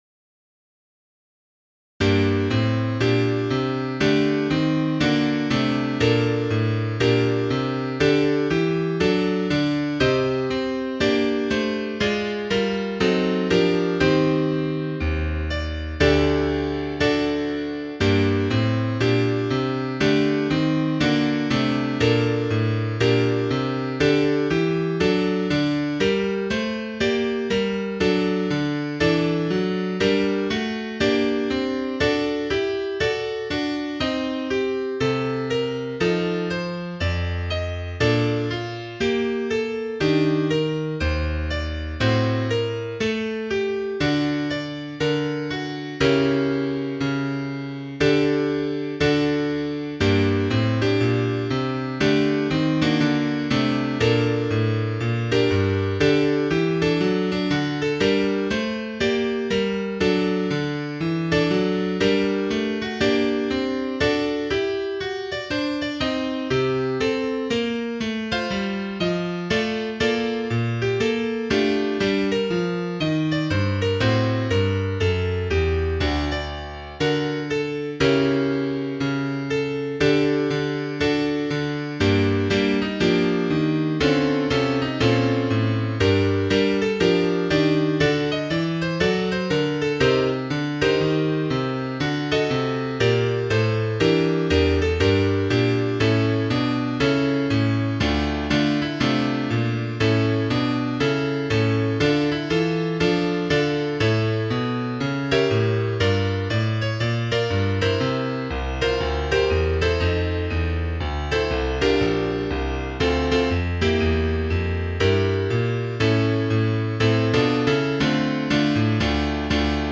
Piano
3_Quia_respexit_Piano.mp3